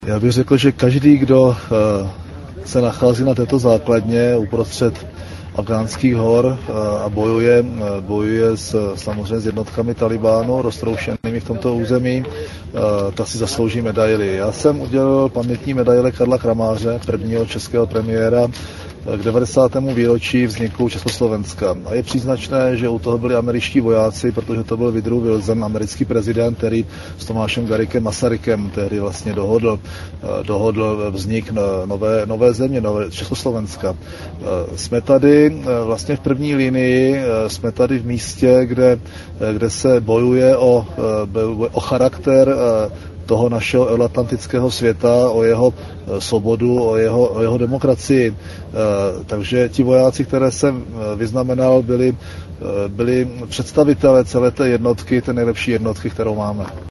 Níže uvádíme zvukový záznam rozhovoru premiéra u příležitosti návštěvy našich vojáků v Afghánistánu na předsunuté základně v Kandaháru
Rozhovor premiéra u příležitosti návštěvy našich vojáků v Afghánistánu na předsunuté základně v Kandaháru v den 90. výročí vzniku samostatného státu